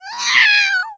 angry-meow
angry-meow.flac